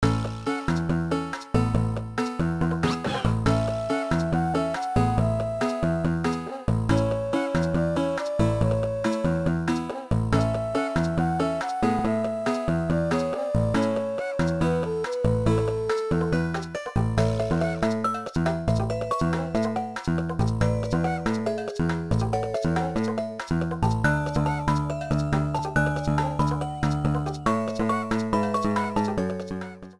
Self-recorded from emulator
Fair use music sample